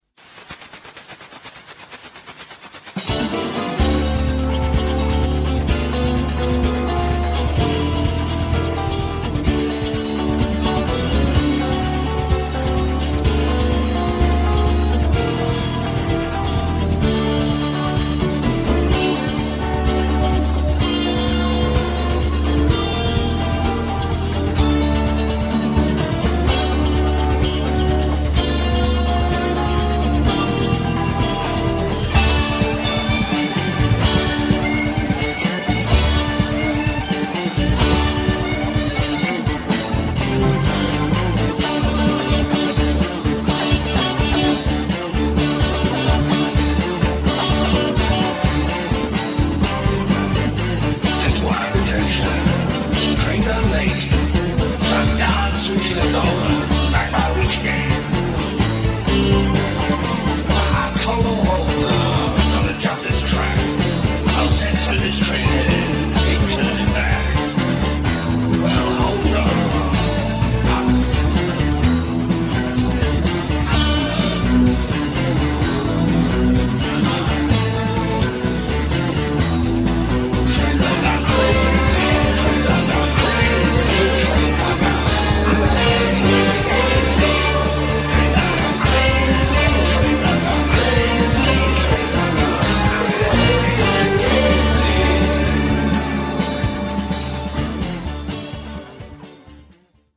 stereo, 5,5 Khz, 32 Kbps, file size: 392 Kb